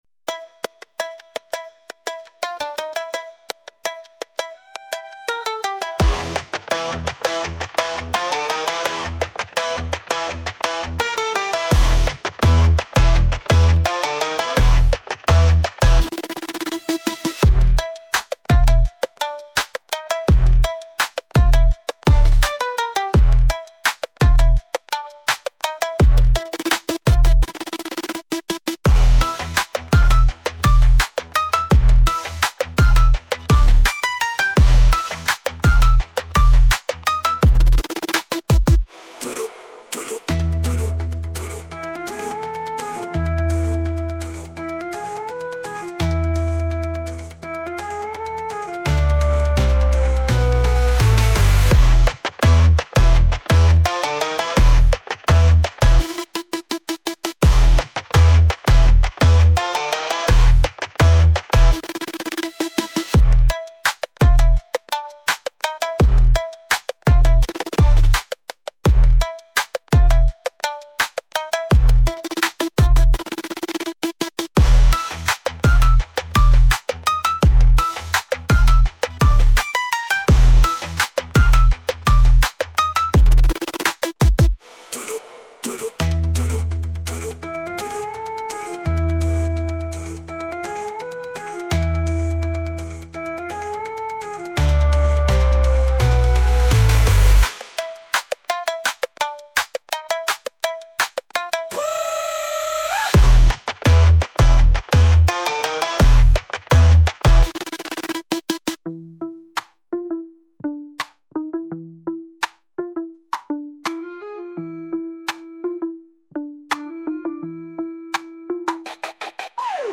Восточный техно-рок